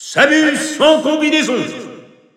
Announcer pronouncing Zero Suit Samus's name in French.
Zero_Suit_Samus_French_Announcer_SSBU.wav